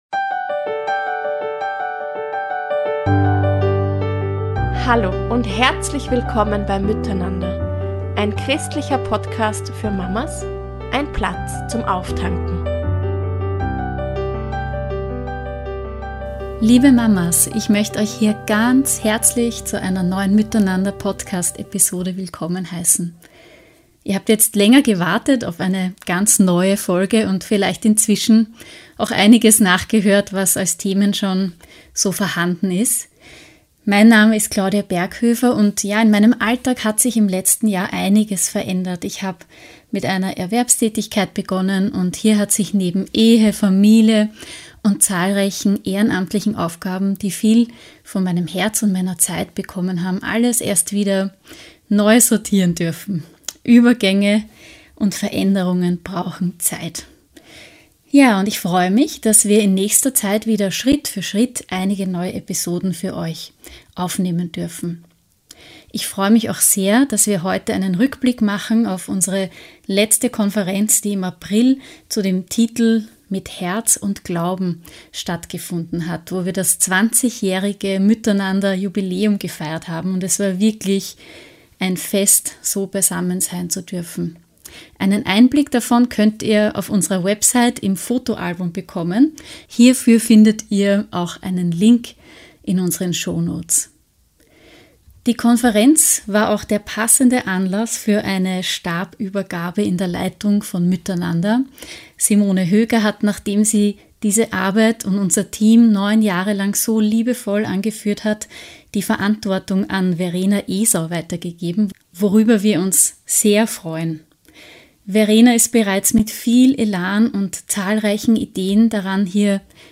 Nach einer längeren Pause melden wir uns mit dieser neuen Episode zurück und möchten dir einen Vortrag unserer letzten MÜTTERNANDER – Konferenz 2025 zu Verfügung stellen, bei der wir unter anderem unser 20- jähriges Bestehen so richtig gefeiert haben!!!